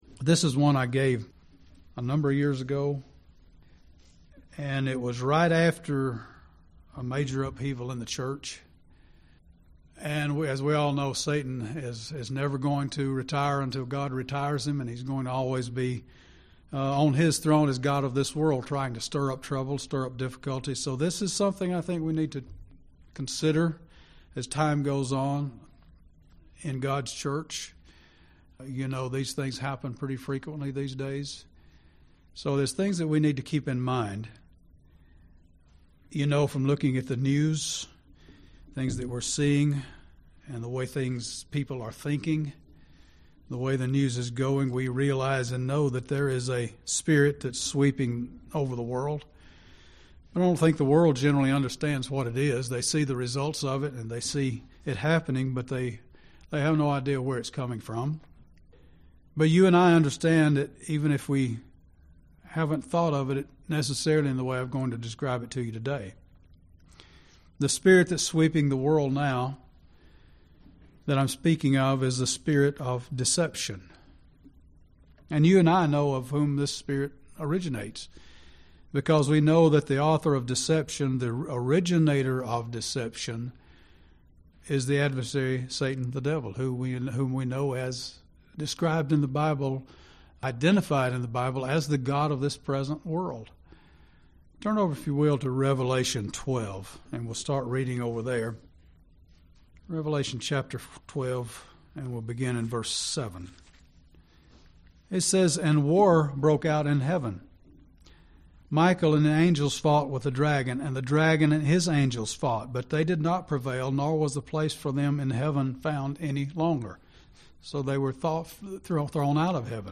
This sermon delves into the topic of spirtual deception and examines what we should do to be sure that we are not carried away by satan's deceptive powers.
Given in Gadsden, AL